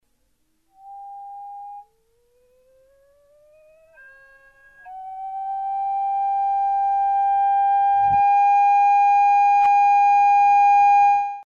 Ecouter l'attaque d'un tuyau de doublette, le temps d'attaque a été considérablement allongé en mettant le ventilateur en route afin d'établir lentement la pression: